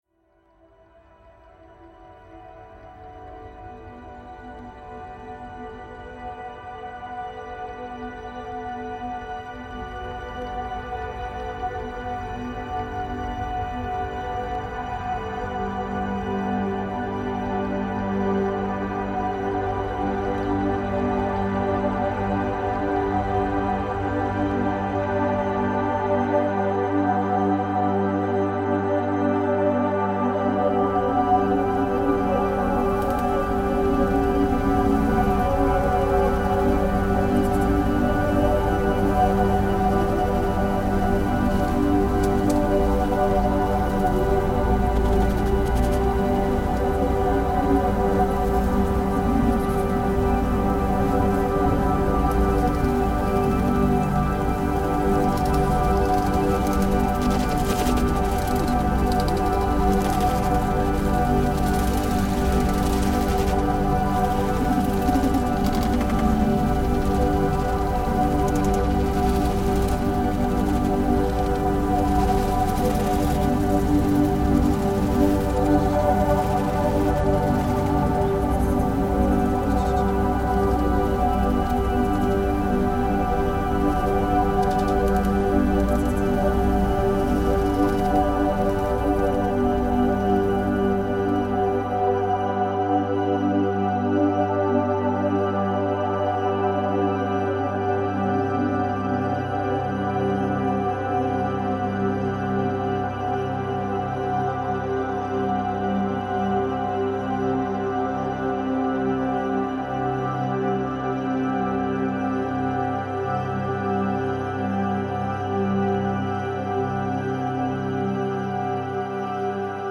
Moldovan bus ride reimagined